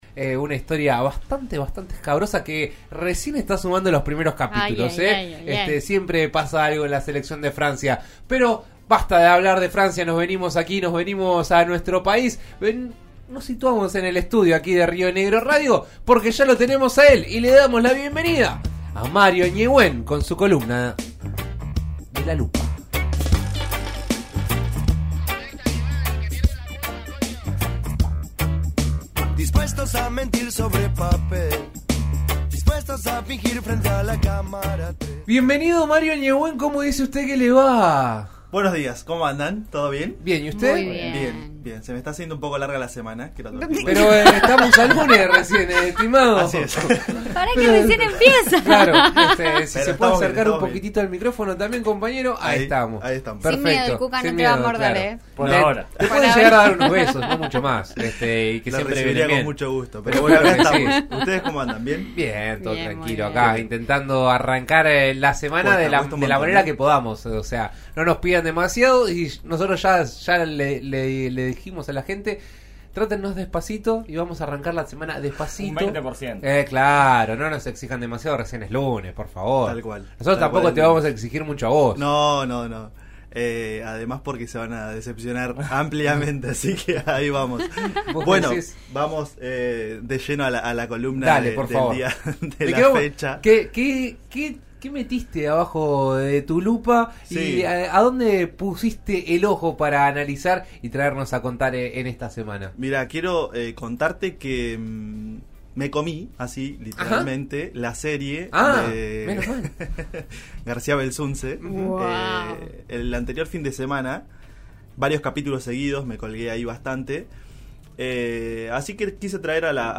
En La Lupa, la columna de En Eso Estamos de RN Radio, analizamos por qué el caso aún sigue tan vigente en los distintos medios de comunicación.